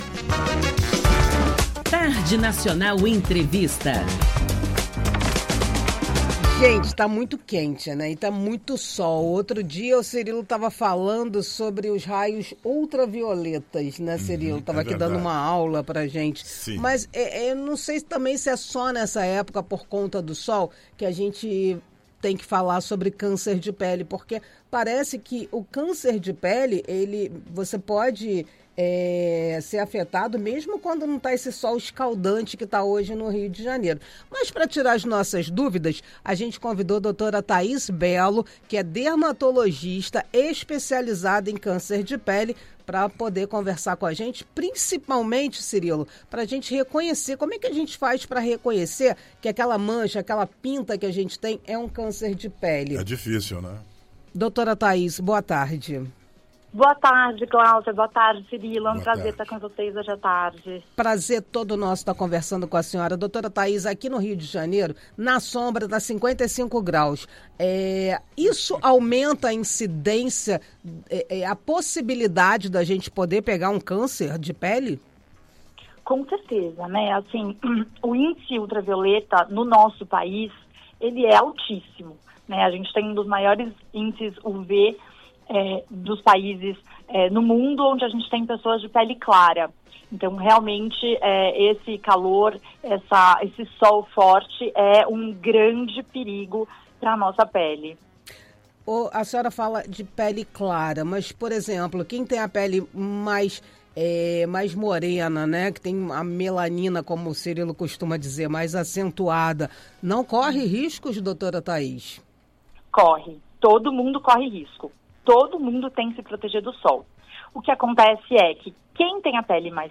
Entrevista Rádio Nacional do Rio de Janeiro (áudio)